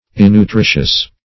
Innutritious \In`nu*tri"tious\, a. Not nutritious; not furnishing nourishment.